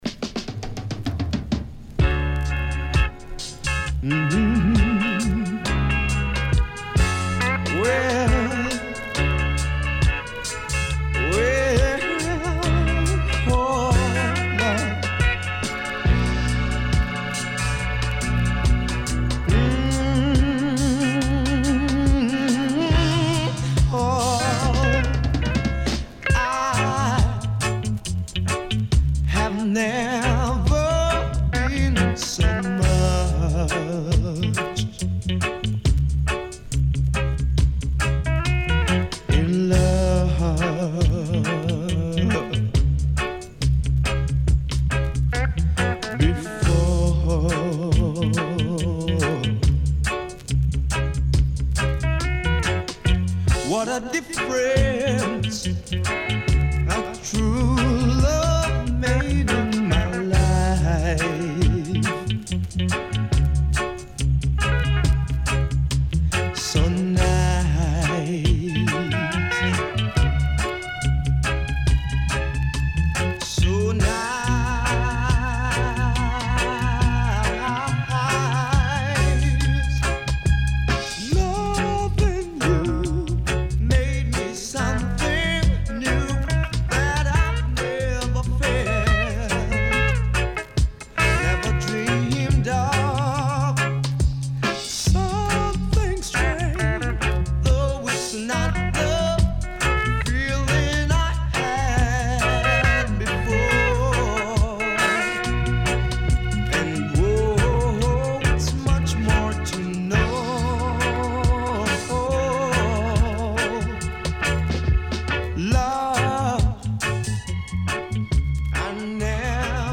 Sweet Soulful Ballad